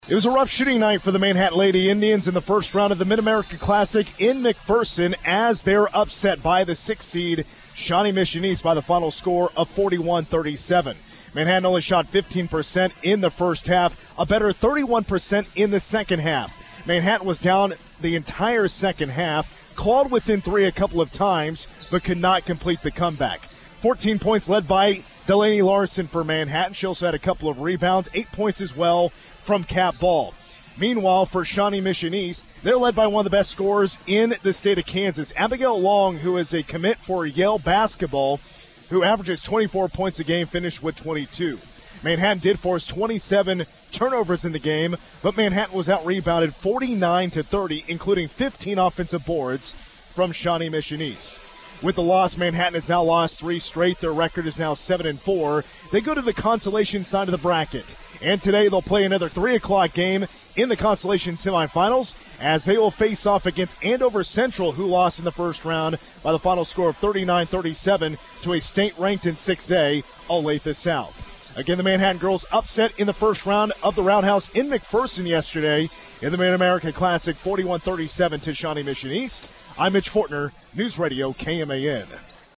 Game recap
Manhattan-vs-SME-recap.mp3